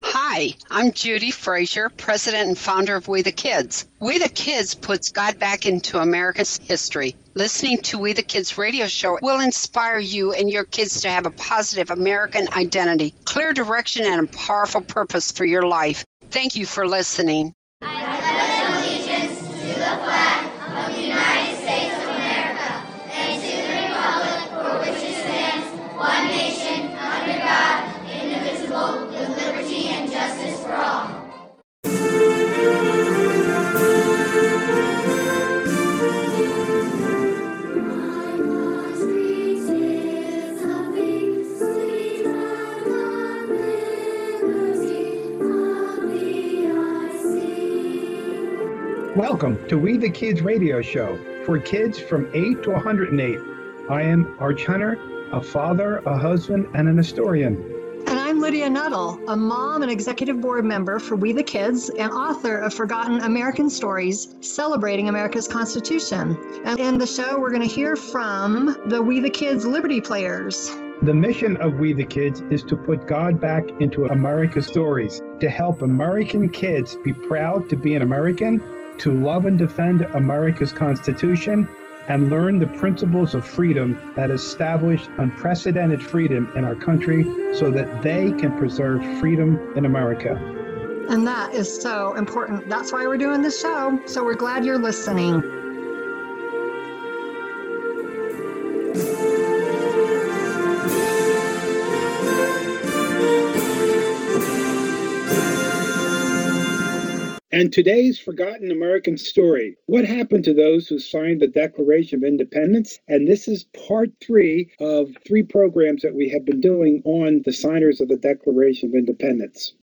In a dynamic historical reenactment, the WeTheKids Liberty Players transport listeners back in time for an interview with Abigail Adams , wife of the second U.S. President John Adams.